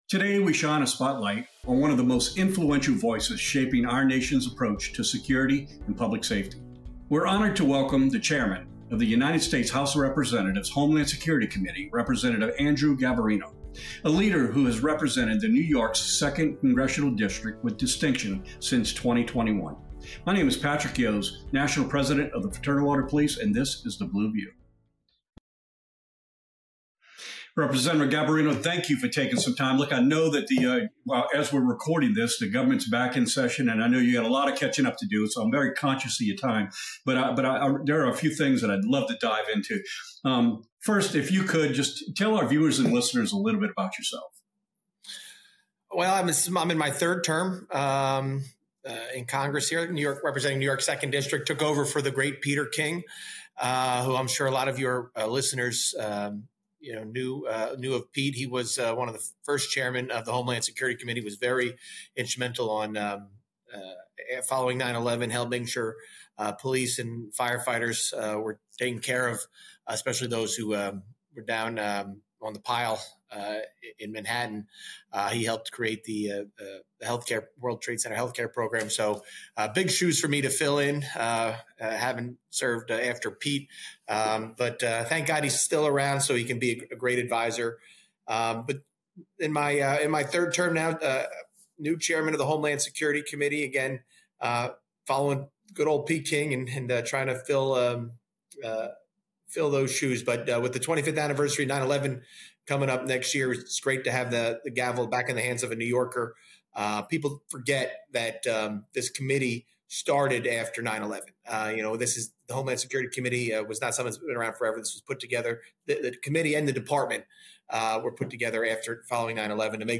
The Blue View:Securing America: A Conversation with Homeland Security Chairman Rep. Andrew Garbarino